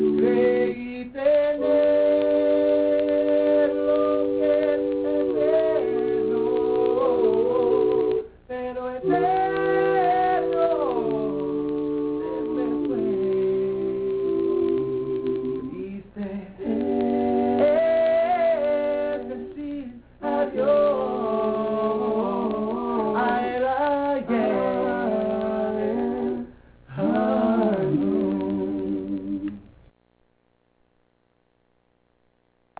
Live Clips